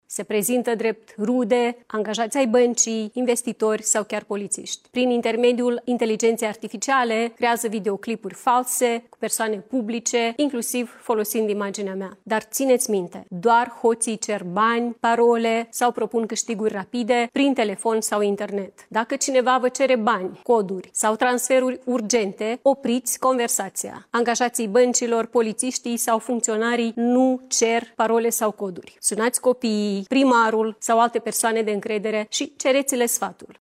Președinta Republicii Moldova, Maia Sandu: „Dacă cineva vă cere bani, coduri sau transferuri urgente, opriți conversația”